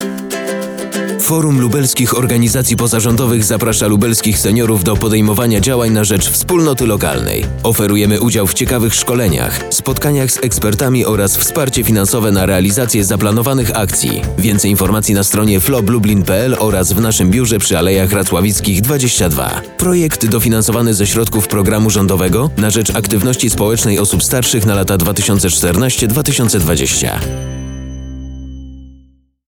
Do odsłuchania spoty radiowe reklamujące kampanię społeczną „Proaktywni 60+ na START”, które zostały wyemitowane w Polskim Radiu Lublin:
Spot reklamowy 1